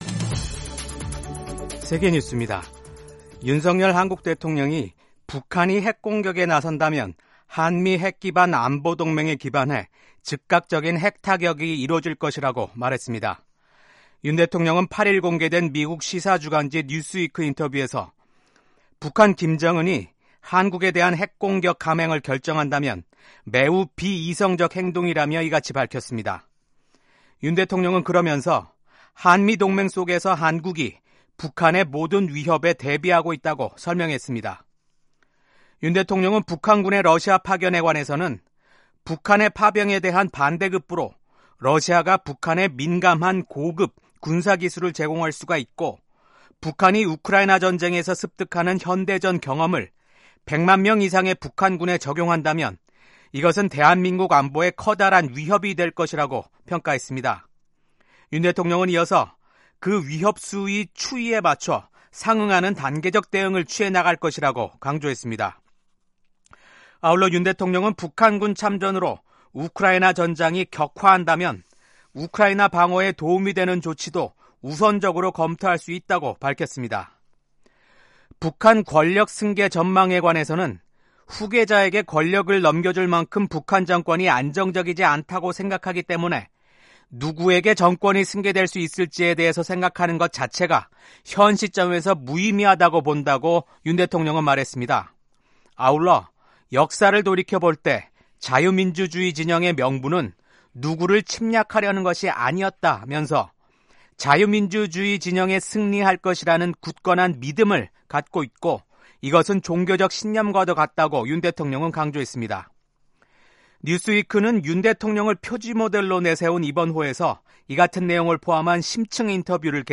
세계 뉴스와 함께 미국의 모든 것을 소개하는 '생방송 여기는 워싱턴입니다', 2024년 11월 9일 아침 방송입니다. 미국 공화당의 도널드 트럼프 대통령 당선인이 수지 와일스 공동선거대책위원장을 비서실장으로 지명했습니다. 미국 중앙은행인 연방준비제도(Fed·연준)가 기준금리를 0.25%P 내렸습니다. 필리핀이 프랑스 지원으로 해경 순찰선 40척을 도입해 이 가운데 일부를 남중국해 분쟁 지역에 배치할 계획이라고 밝혔습니다.